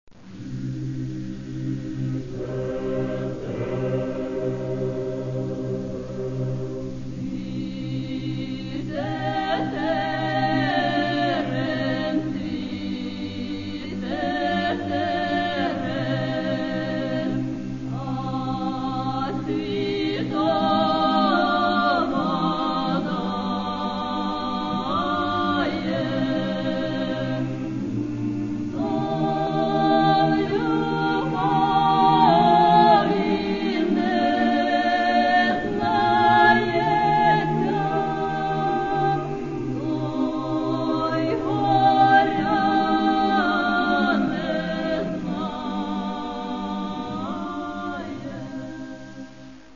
Каталог -> Народная -> Акапельное пение и хоры